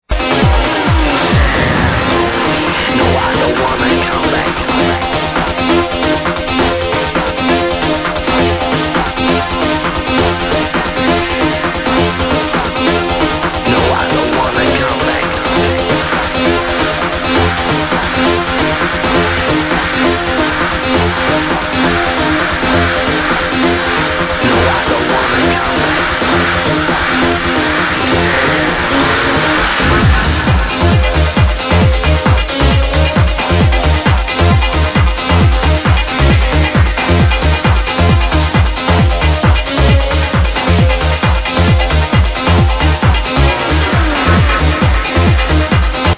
very good old trance song and very hard to ID!!
Please help me ID this great oldschool hardtrance song!